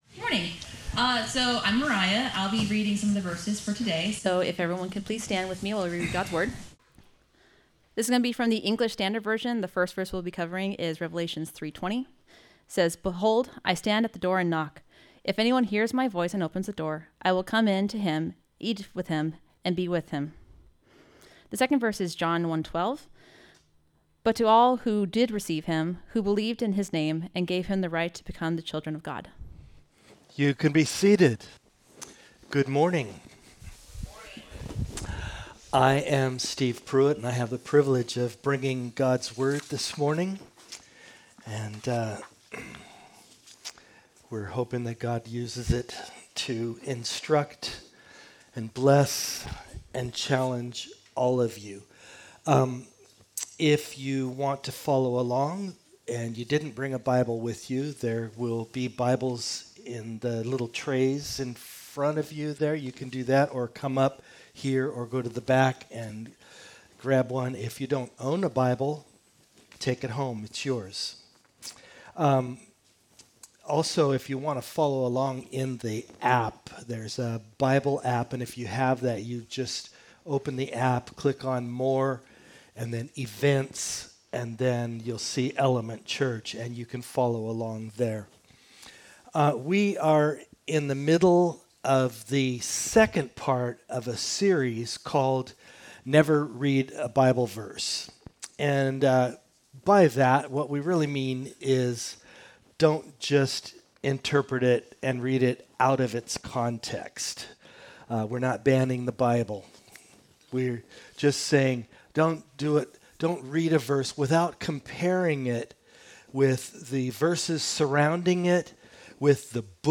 Service Audio Two verses—John 1:12 and Revelation 3:20—are commonly used together to teach that salvation comes through asking Jesus into your heart.